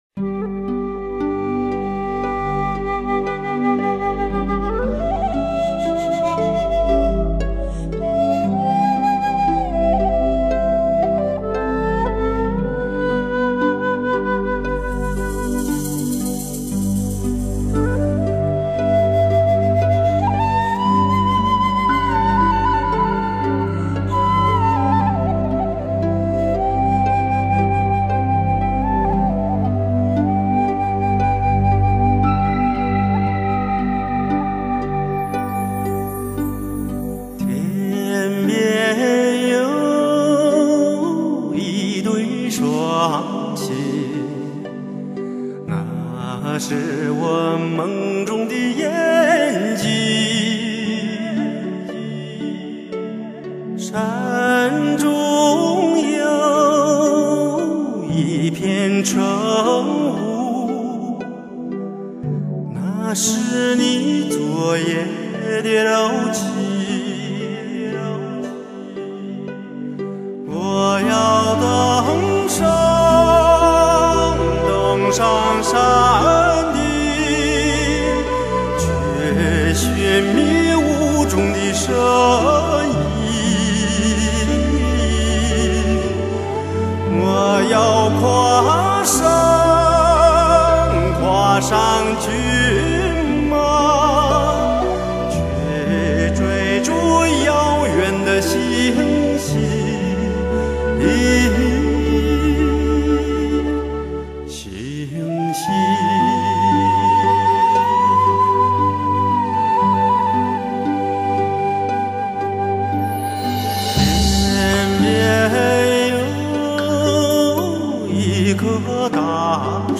其试音碟也是精选多家发烧唱片公司的精彩录音作品.